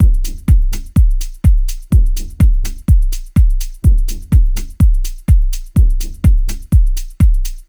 • house - techno beat passage 125bpm - Am - 125.wav
A loop that can help you boost your production workflow, nicely arranged electronic percussion, ready to utilize and royalty free.